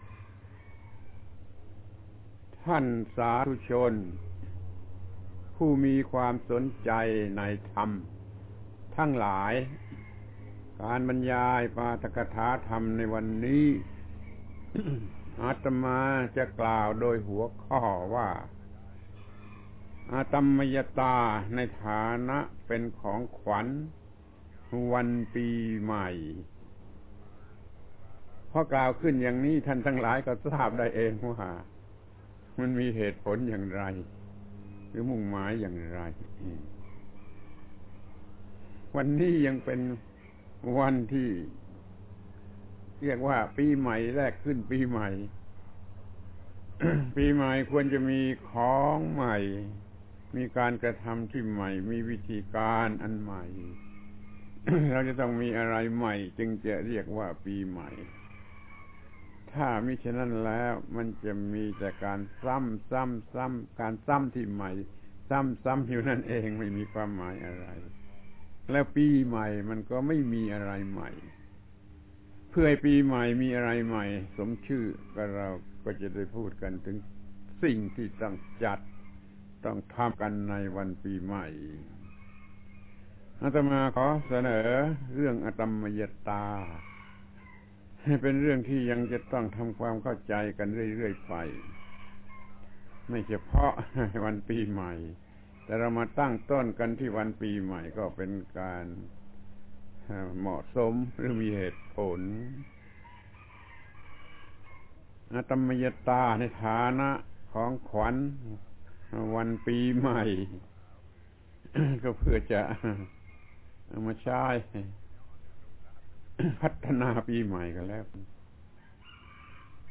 ปาฐกถาธรรมทางวิทยุ ฯ อตัมมยตา อตัมมยตาในฐานะเป็นของขวัญวันปีใหม่